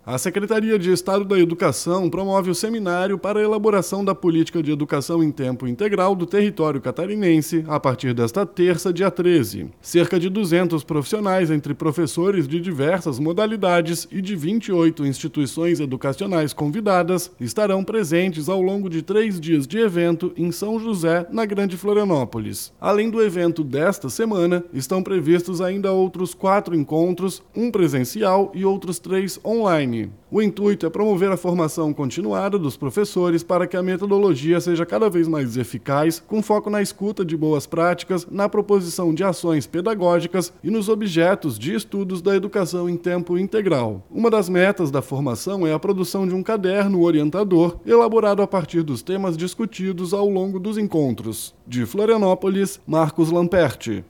BOLETIM – Secretaria da Educação promove seminário para elaboração de política educacional de tempo integral em SC